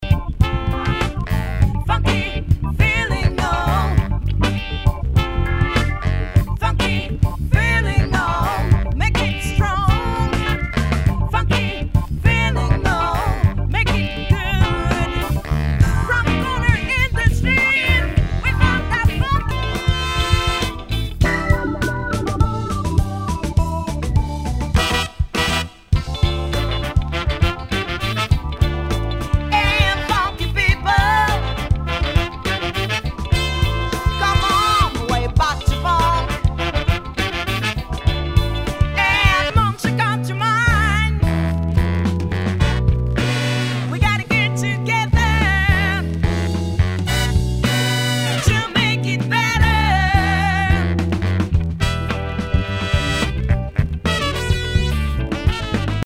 SOUL/FUNK/DISCO
全体にチリノイズが入ります。